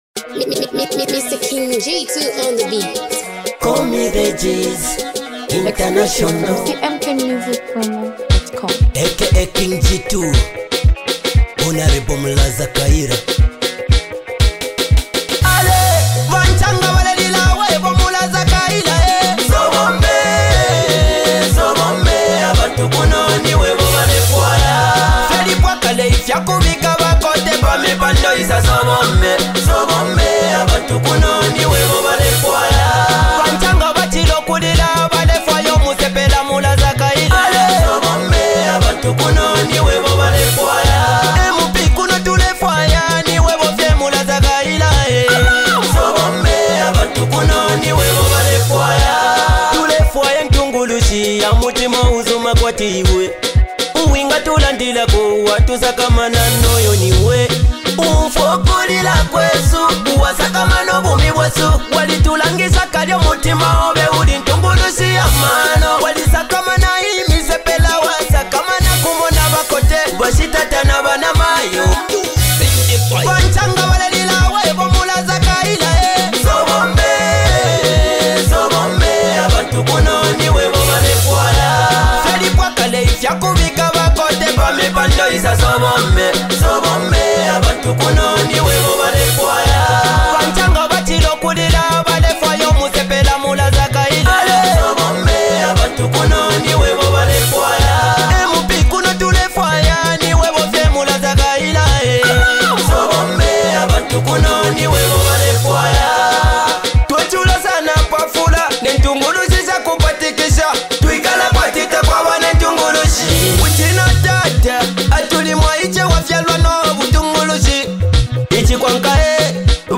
campagn song